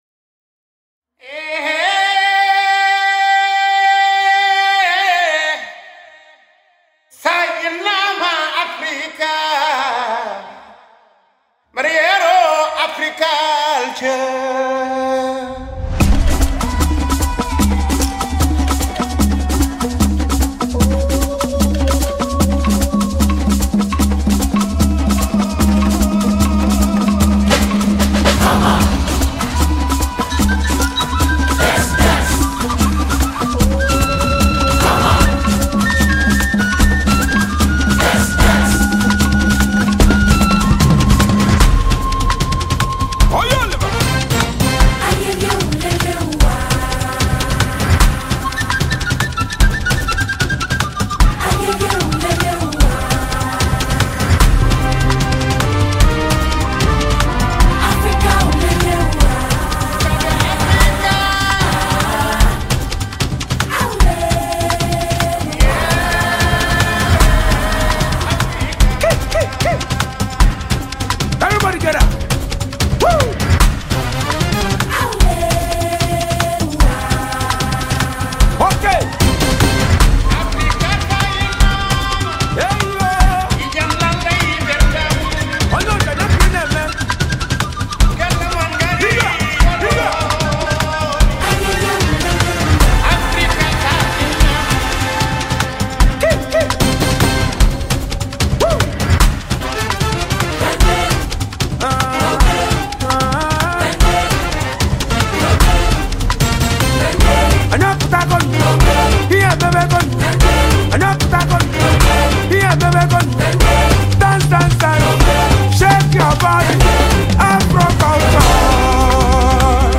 smooth highlife
traditional style